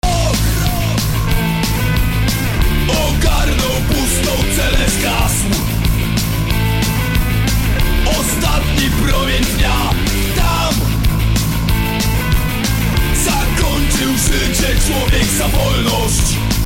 Вот - 1 песня, которая не супер сведена, но мне нравится как звучит.